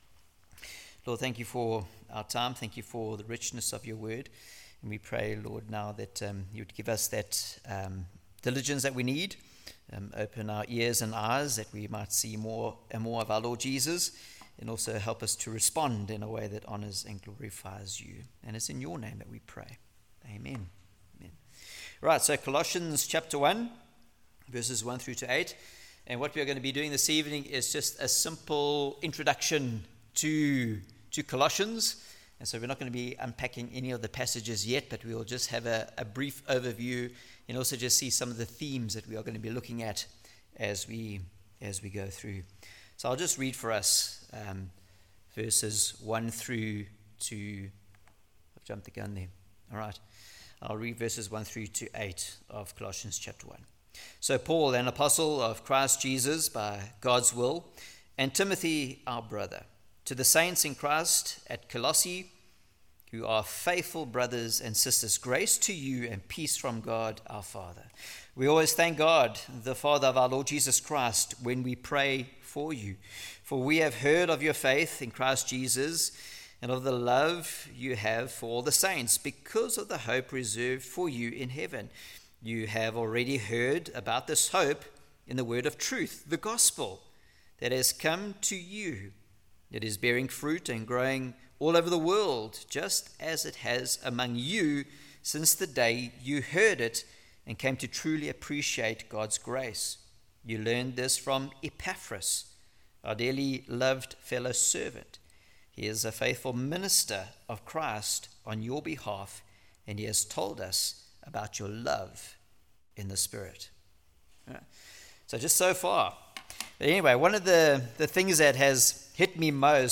Colossians Passage: Colossians 1:1-8, Acts 19:1-10 Service Type: Sunday Evening Planted Purpose Praise « I Would Do Anything For That!